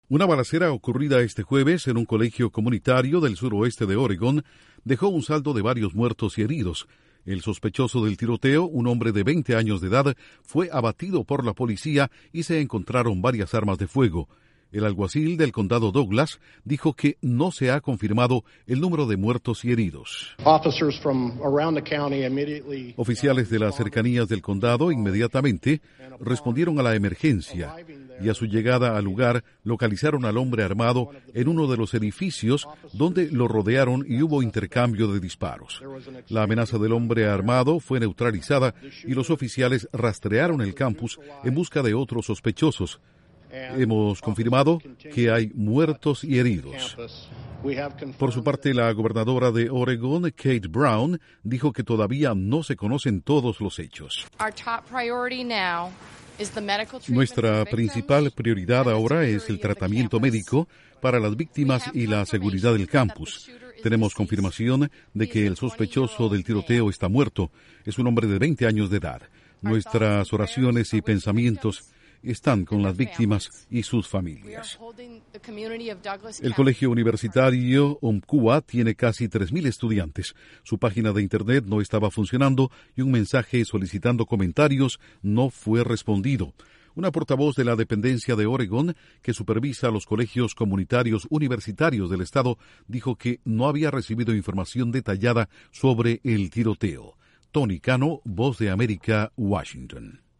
Un tiroteo en un colegio comunitario de Oregón deja un saldo de varios muertos y heridos. Reportes iniciales indican que hay diez víctimas mortales. Informa desde la Voz de América